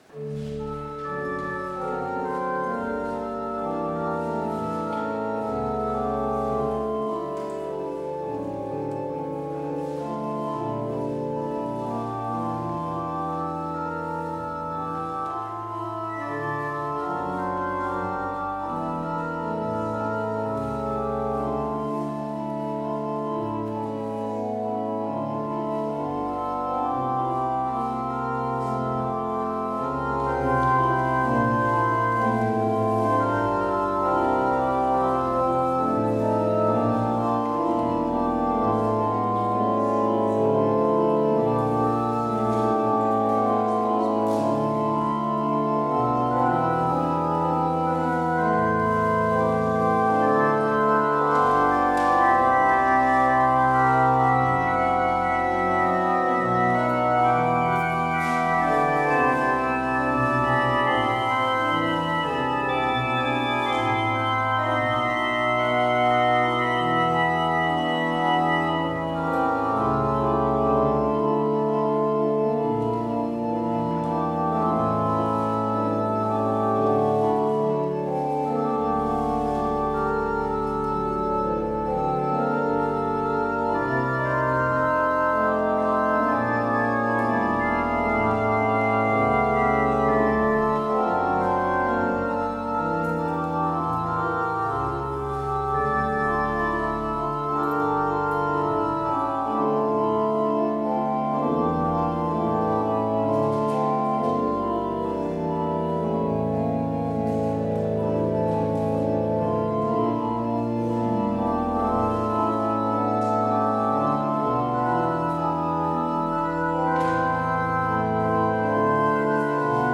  Luister deze kerkdienst terug